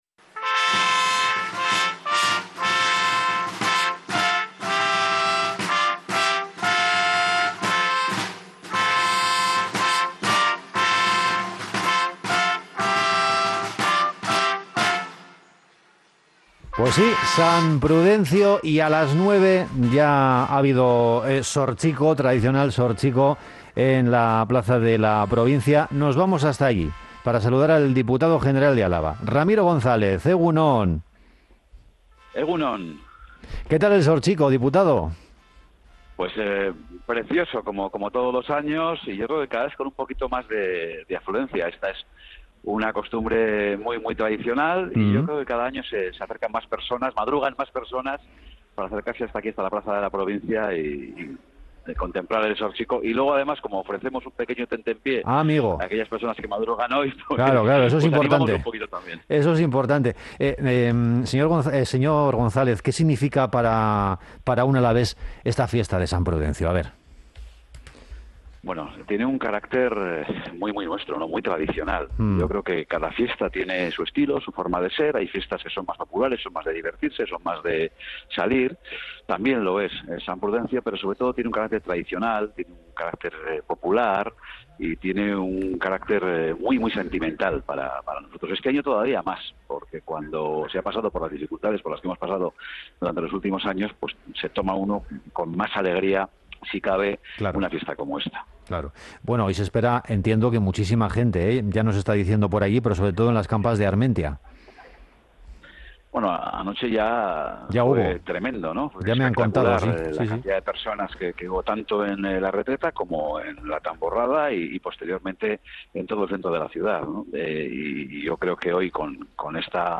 El diputado general de Araba en funciones, Ramiro González, que aspira la reelección el próximo 28 de mayo, ha pasado esta mañana por los micrófonos de Onda Vasca, inmerso ya de pleno en la celebración del día de San Prudencio, fiesta en el territorio alavés.